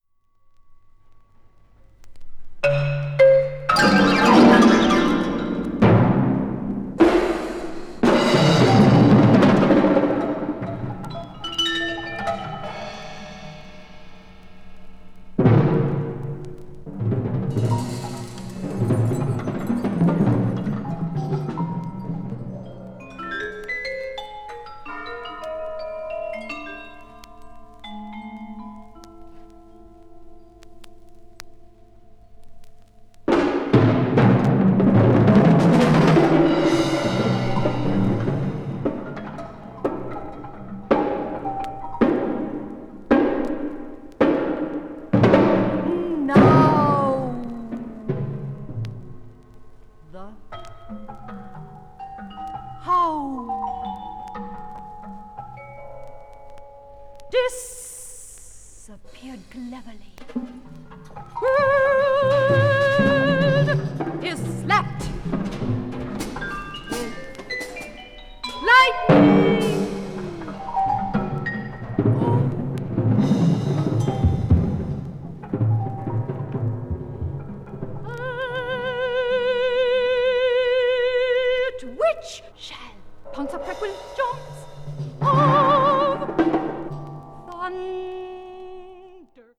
media : EX-/EX-(薄いスリキズによるわずかなチリノイズ/一部軽いチリノイズが入る箇所あり)